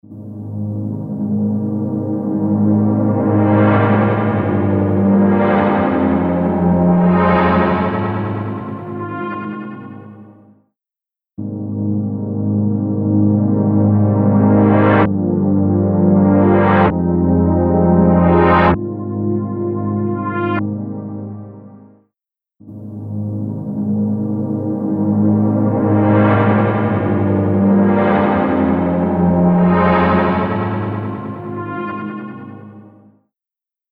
H910 Harmonizer | Synth | Preset: Play Chords
H910-Harmonizer-Eventide-Synth-Pad-Play-Chords.mp3